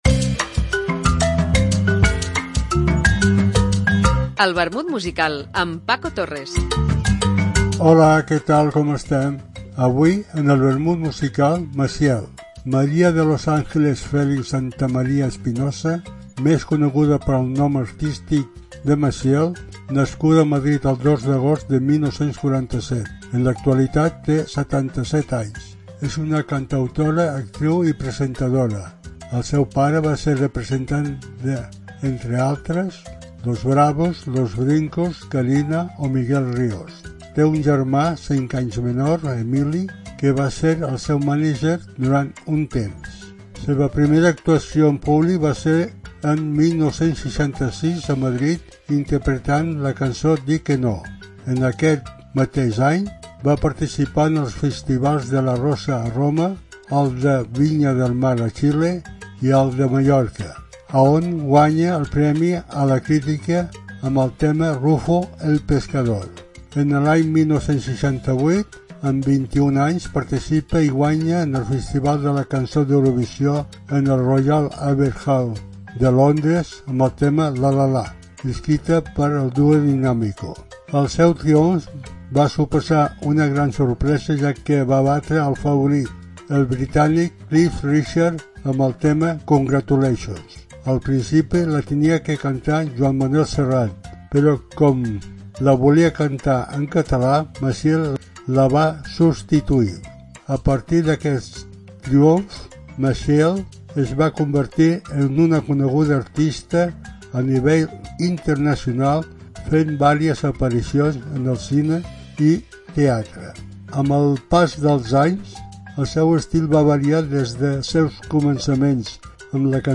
Una apunts biogràfics acompanyats per una cançó.